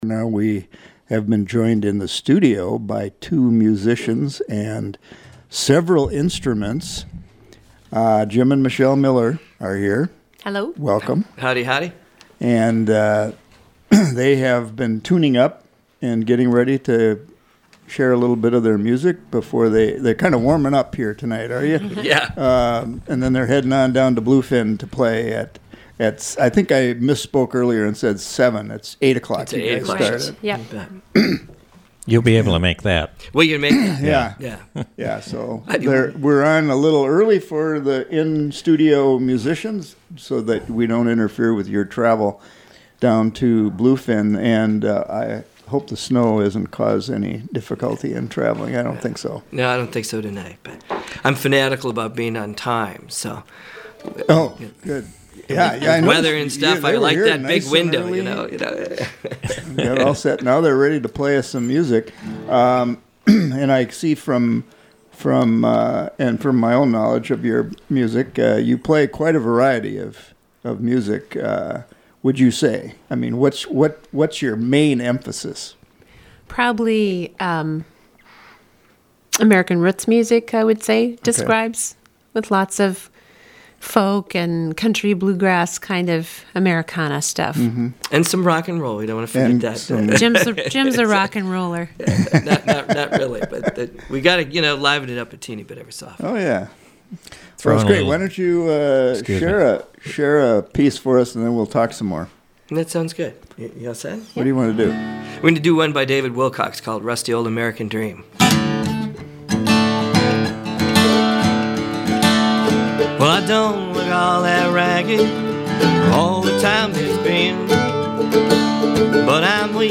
make great music in Studio A
a husband-wife duo from Hovland
They stopped by The Roadhouse Jan. 2 on their way to a gig in Tofte. Program: Live Music Archive The Roadhouse